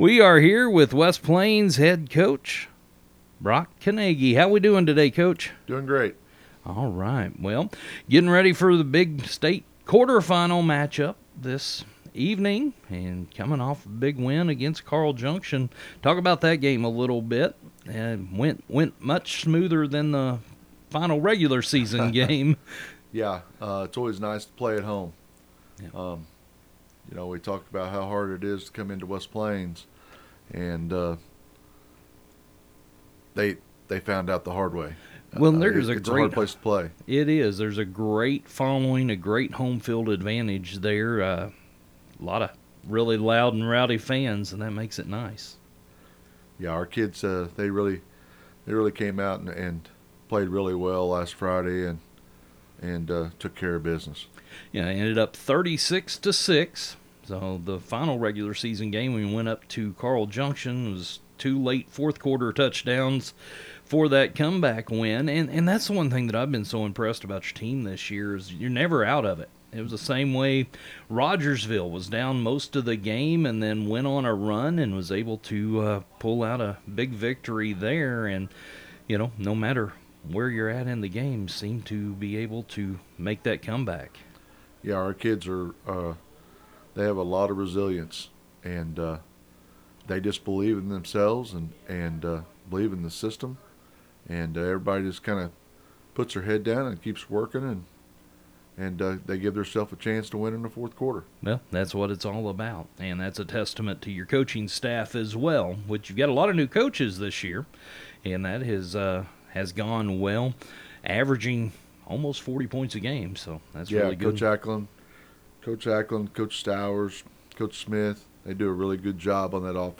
Coach stopped by the Q 94 studio Friday morning to talk about last weeks win and this weeks opponent.